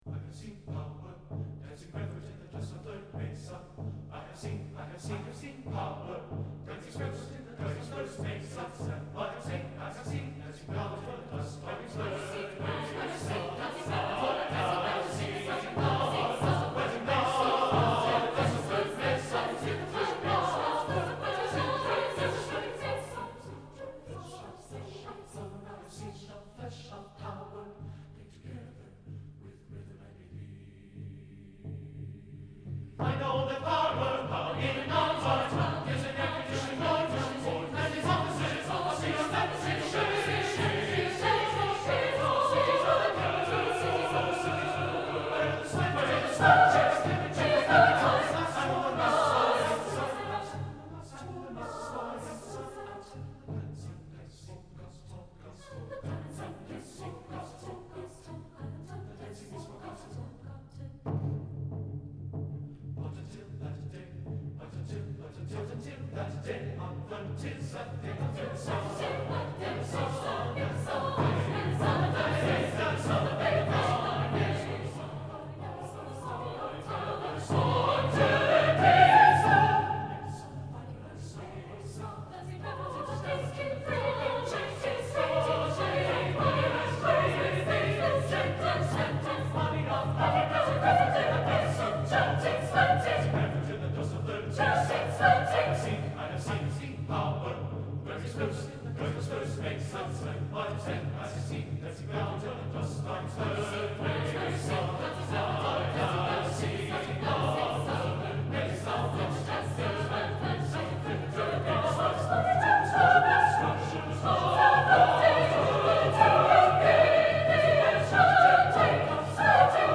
SSAATTBB, Native American flute & drum; rainstick